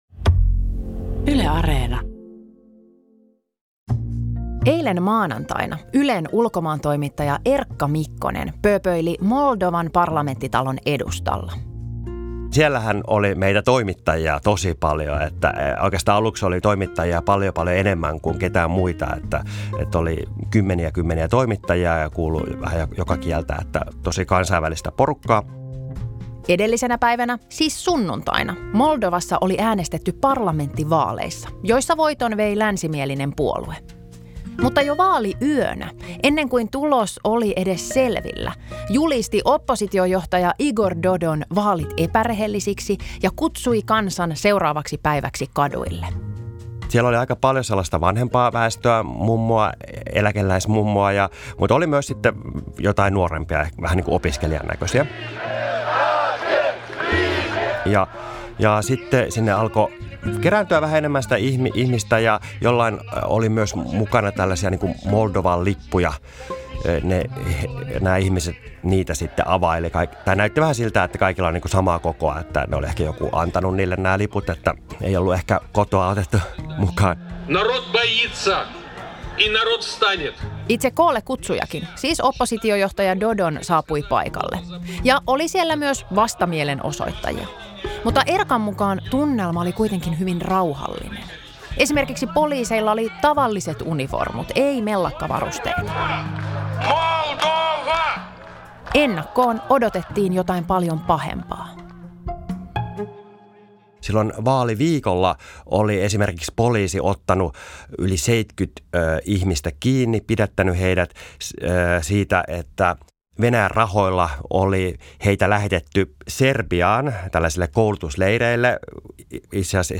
haastateltavina asiansa tuntevia vieraita.